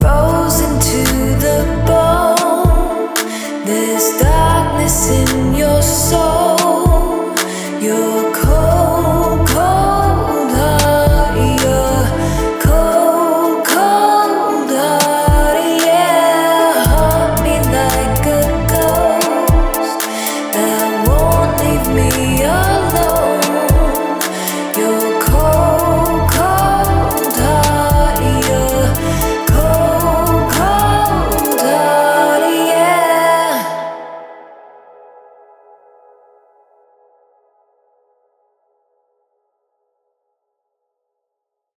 So the differences you’ll hear between different mastering presets are also very small and often have more to do with width, depth, clarity, or punch rather than noticeable loudness.
Now, let’s check out the differences in the sound when I used two different presets on the demo track:
Classic
Mastering-Presets-Demo-Classic-mastering.mp3